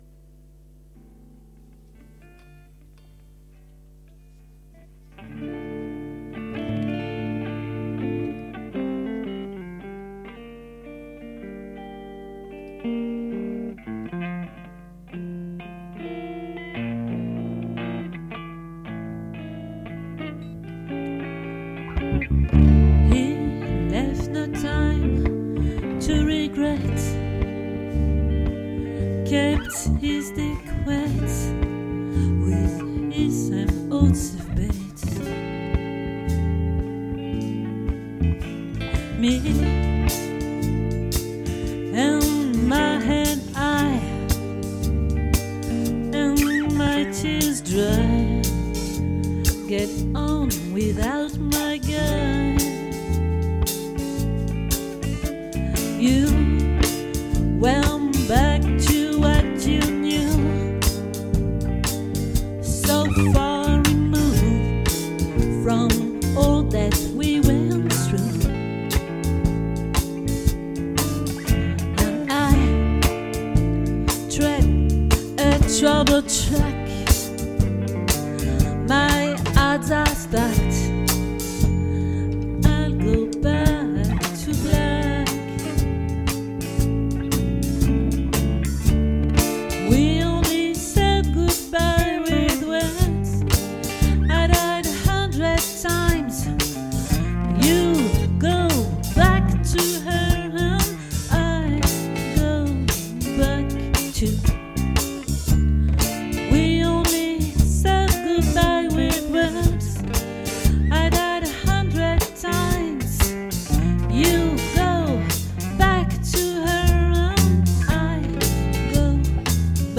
🏠 Accueil Repetitions Records_2022_11_16_OLVRE